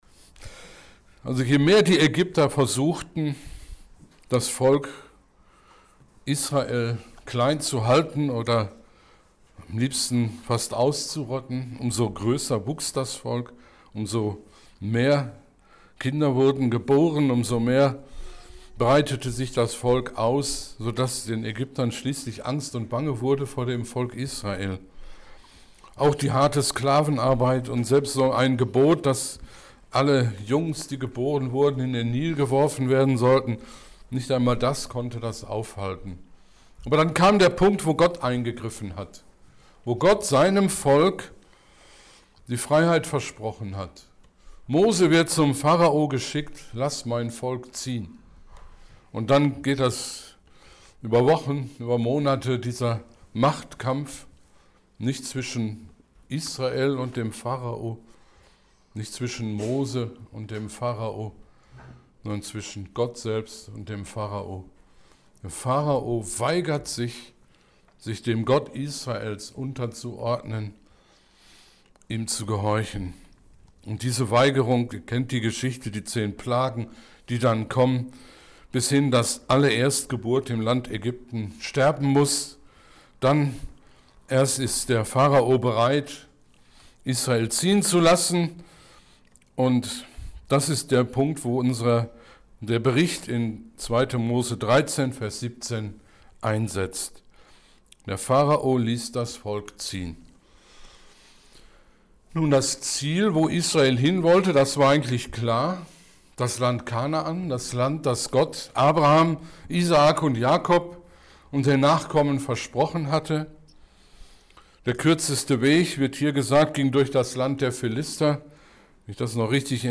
Predigt_28.06.2020